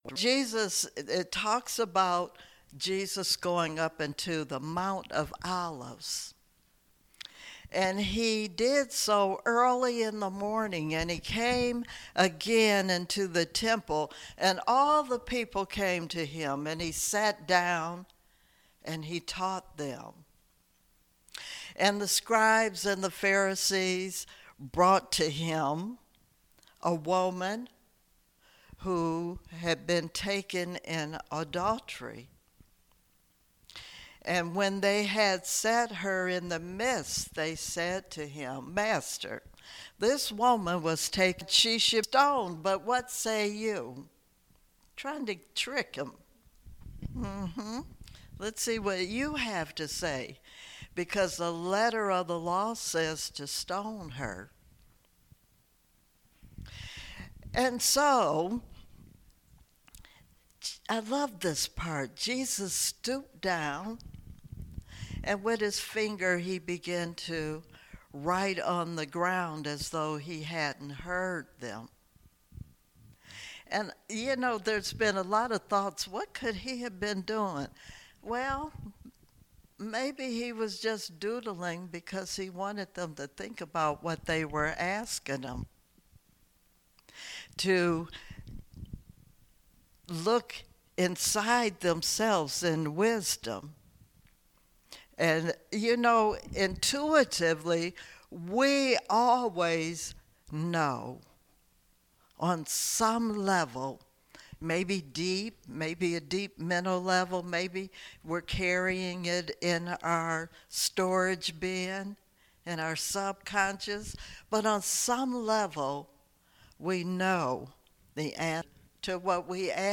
Spiritual Leader Series: Sermons 2021 Date